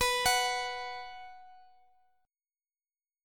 Listen to B5 strummed